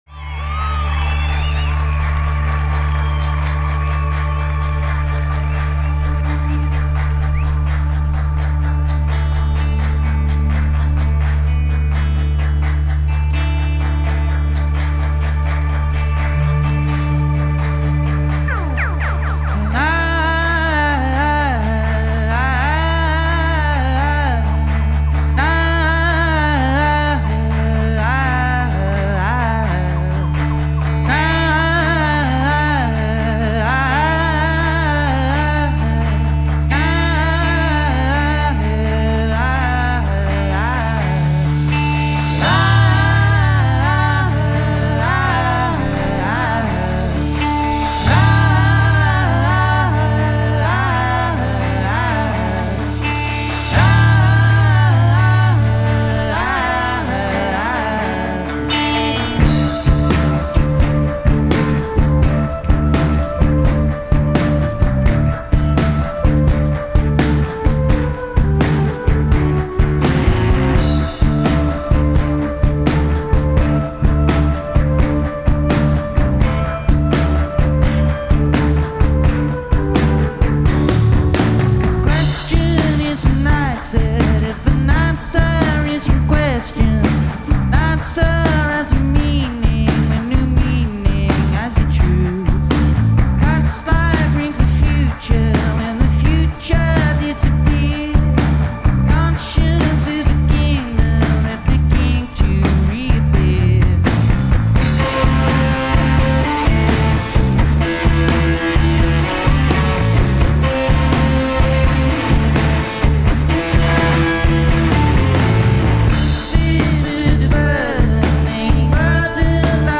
Solid, tight and self-assured.